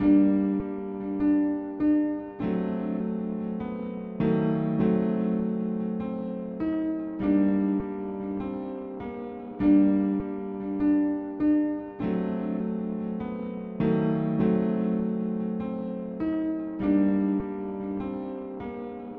卡西欧合成器 "的分层钢琴alt
描述：重复的钢琴旋律在我的卡西欧合成器上演奏。同样的旋律演奏了两种不同的钢琴设置（普通钢琴和慢速钢琴八度音高）。第二次。
标签： 回路 旋律 分层 钢琴
声道立体声